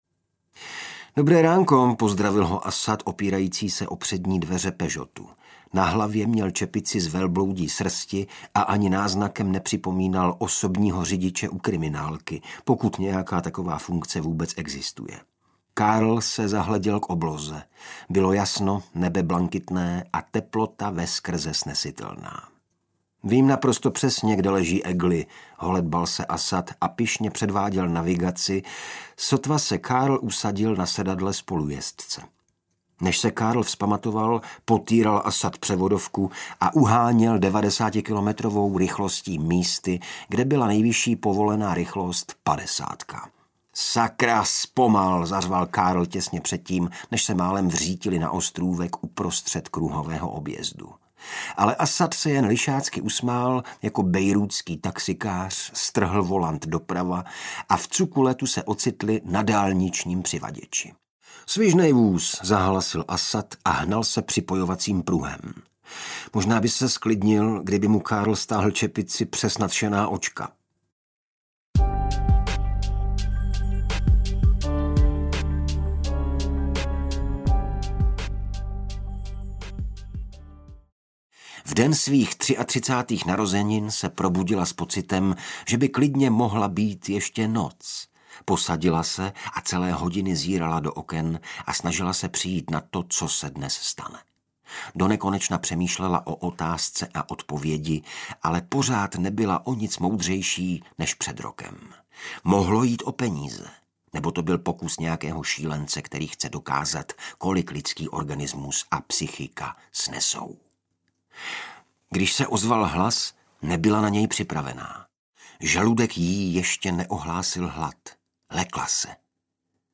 Žena v kleci audiokniha
První případ detektiva Carla Mørcka a jeho asistenta Asada od dánského spisovatele Jussiho Adler-Olsena vychází v interpretaci Igora Bareše.
Ukázka z knihy
• InterpretIgor Bareš